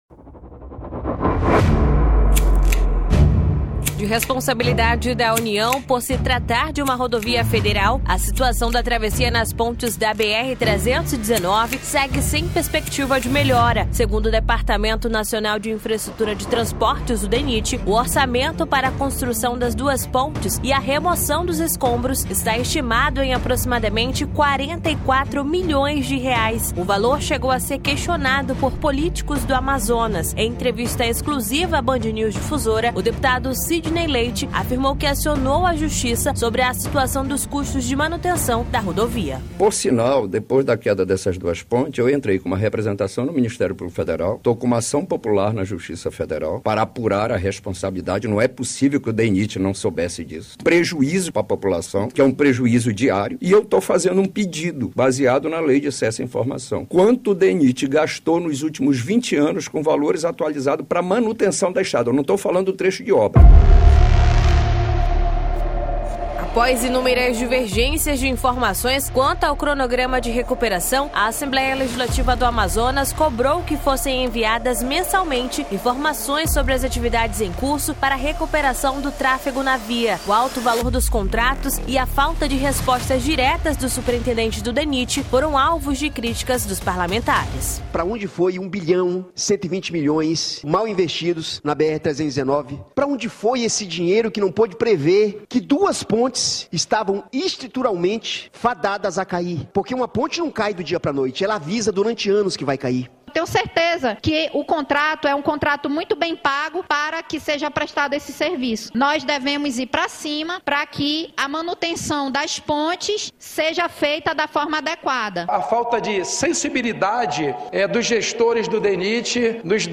Reportagem
Em entrevista exclusiva à BandNews Difusora, o deputado Sidney Leite (PSD), afirmou que acionou a justiça sobre a situação dos custos de manutenção da rodovia.